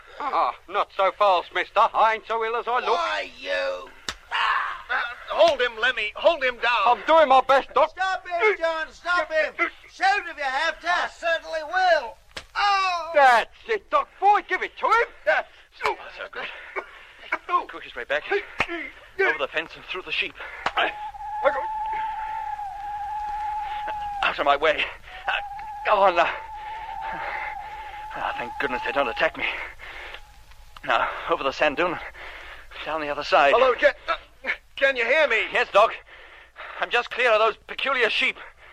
Na de vechtpartij, waarbij Jet/Jeff weet te ontsnappen, rent hij door de omheining met de beesten die schapen moeten voorstellen. Waar ik in het vierde fragment van deel 15 het Britse schapengeluid beter vind passen dan het Nederlandse geluid, komt in dit fragment juist het Nederlandse geluid qua spanning beter uit de verf. Hoewel ik nog steeds geen uilen ken die zo’n geluid maken…